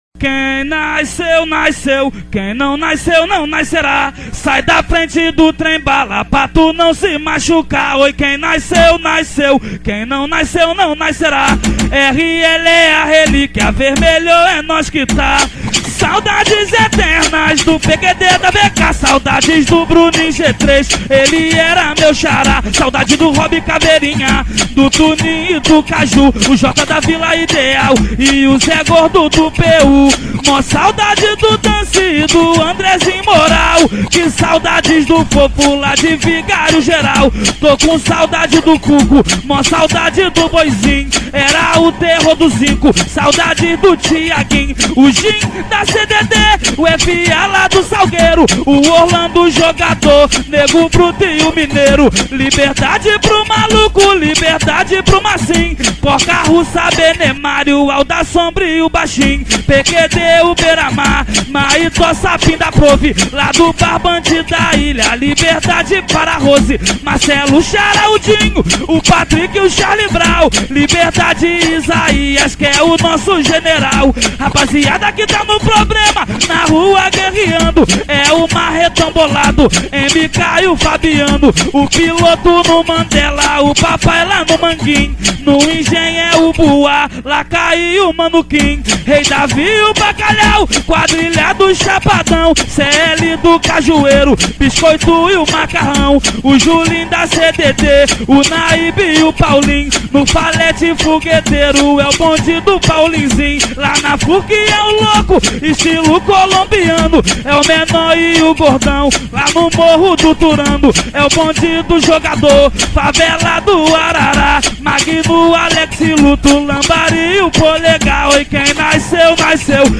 2024-09-17 00:08:57 Gênero: Funk Views